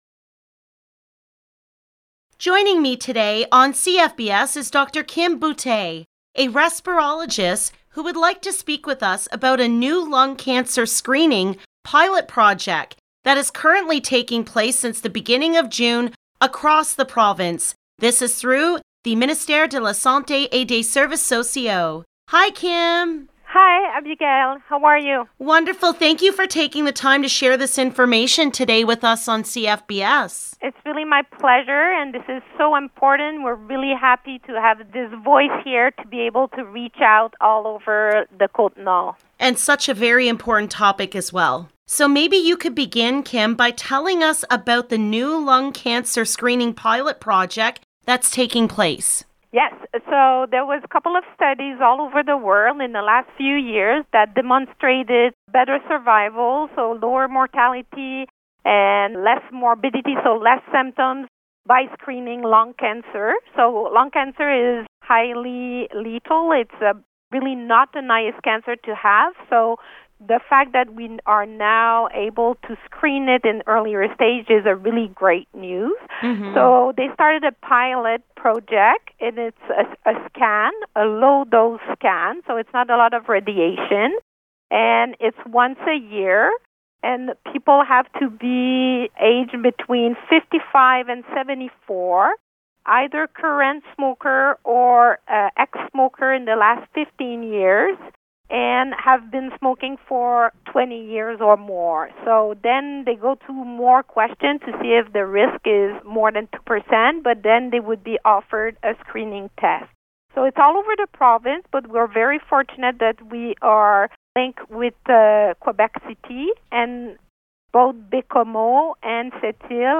LOCAL NEWS - JULY 16, 2021: NORTH SHORE – A LUNG CANCER SCREENING PILOT PROJECT IS NOW TAKING PLACE ACROSS QUEBEC